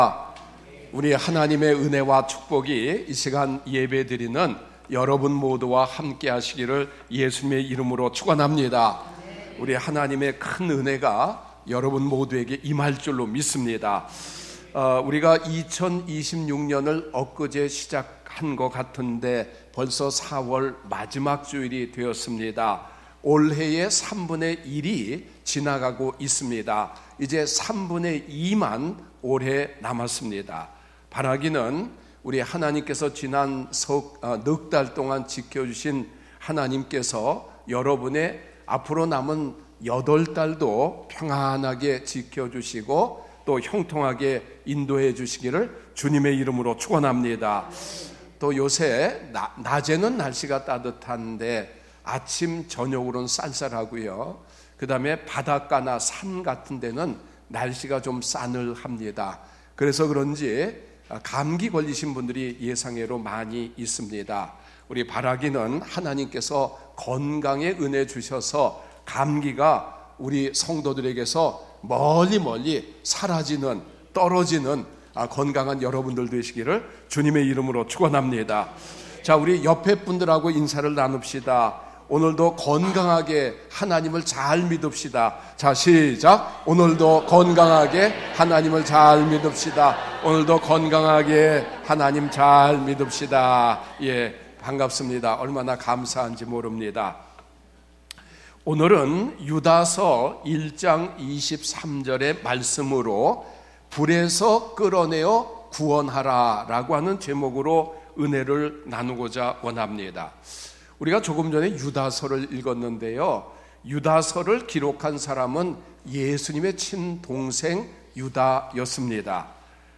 목록 share 주일설교 의 다른 글